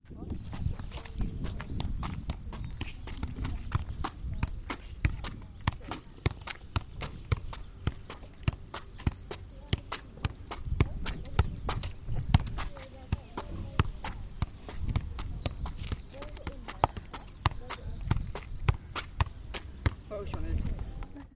Outside the community hall 1, 2, 3, 4, 5, 6, 7......